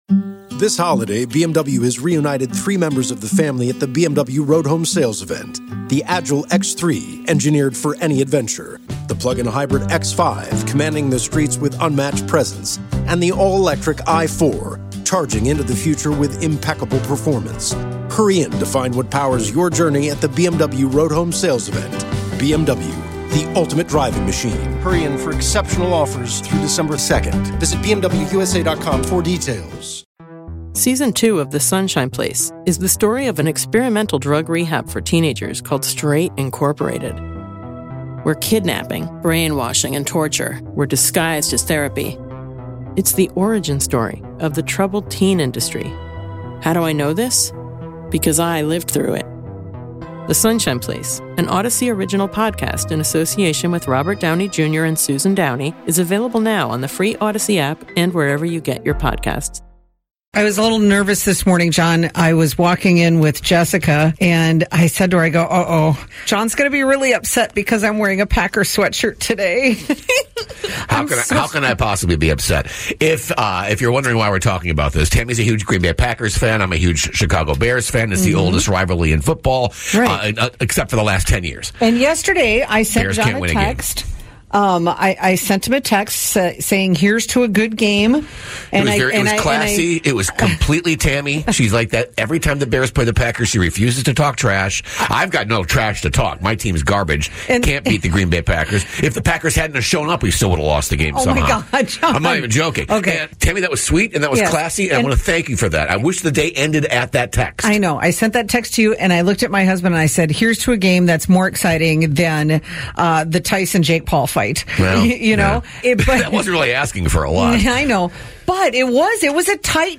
Interviews, favorite moments